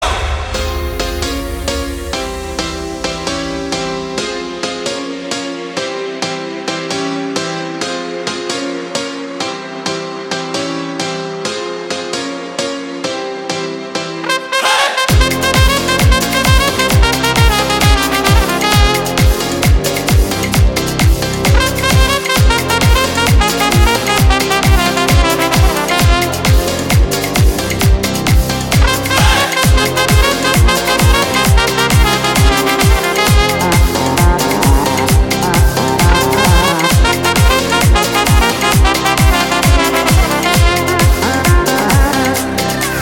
• Качество: 320, Stereo
зажигательные
инструментальные
Зажигательная и ритмичная музыка без слов